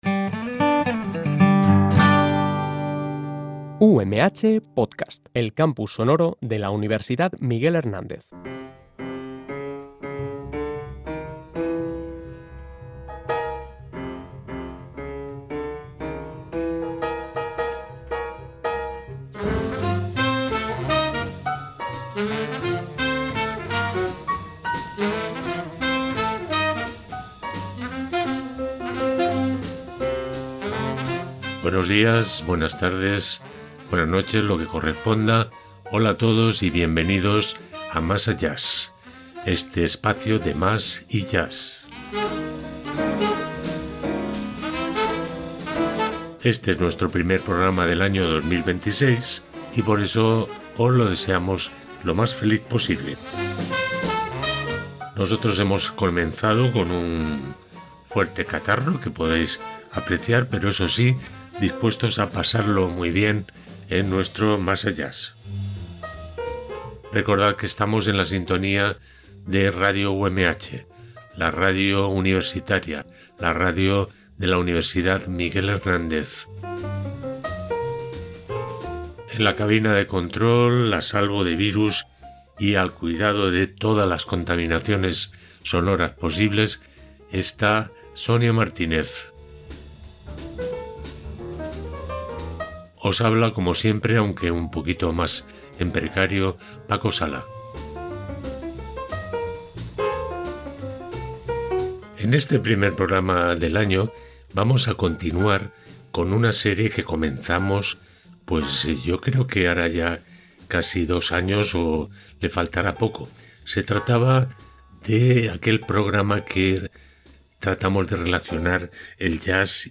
‘Más A’Jazz’ es un espacio de Jazz… y más.